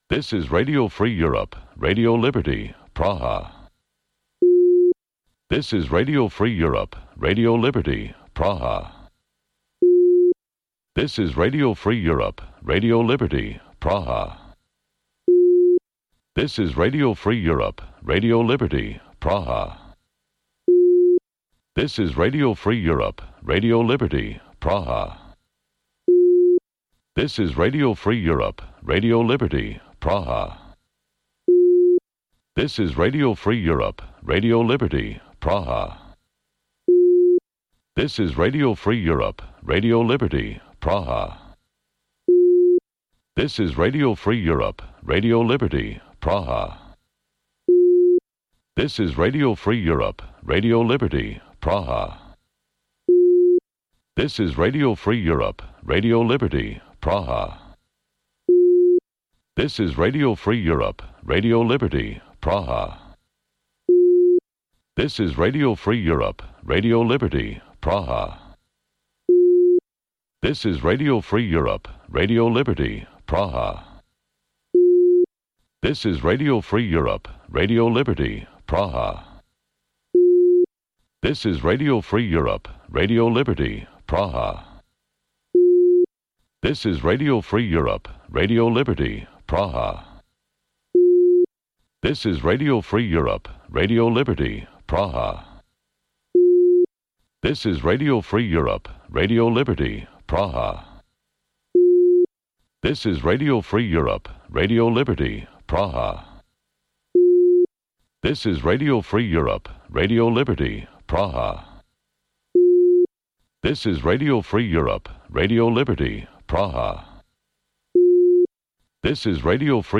Бул үналгы берүү ар күнү Бишкек убакыты боюнча саат 18:00ден 18:30га чейин обого түз чыгат.